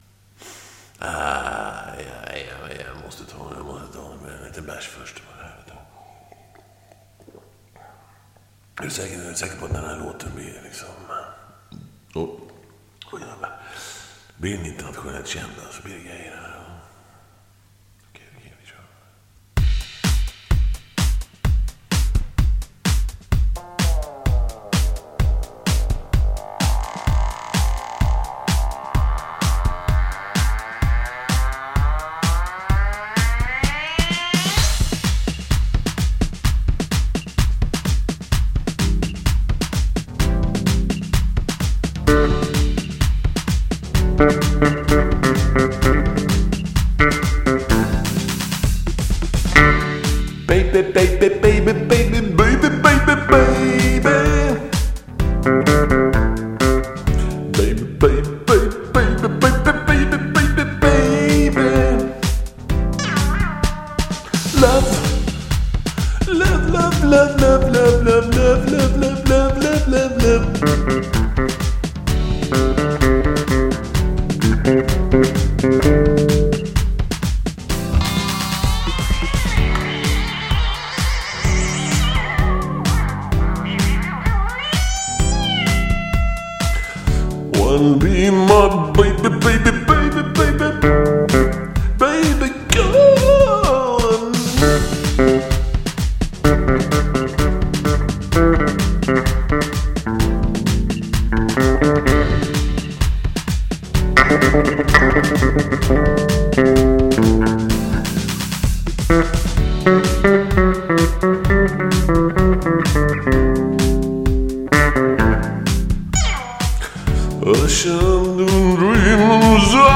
Studio session 161221. All A Capella. The modern sound of it.